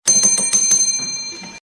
• RECEPTION BELL RINGING.mp3
Recorded in a hotel reception, pretty small, trying to get someone for assistance.
reception_bell_ringing_0bw.wav